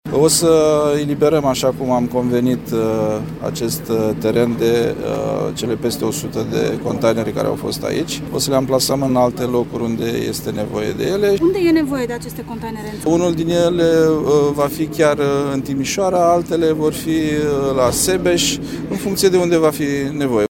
Prezent la operațiunea de dezafectare a containerelor, ministrul Apărării, Angel Tîlvăr, spune că stadionul va fi eliberat în cel mult două săptămâni.